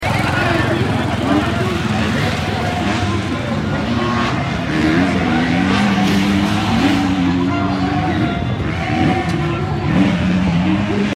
Ducati Desmo 450 MX MXGP Sound Effects Free Download